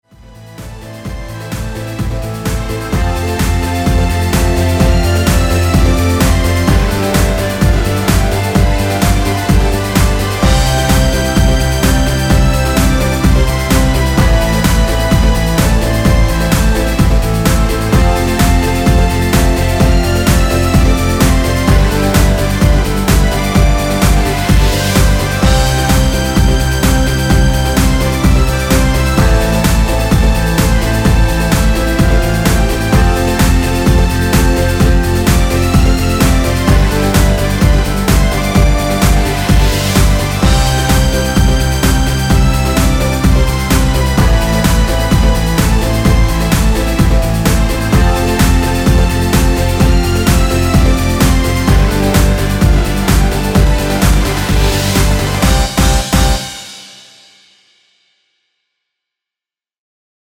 엔딩이 페이드 아웃이라 엔딩을 만들어 놓았습니다.(미리듣기 확인)
◈ 곡명 옆 (-1)은 반음 내림, (+1)은 반음 올림 입니다.
앞부분30초, 뒷부분30초씩 편집해서 올려 드리고 있습니다.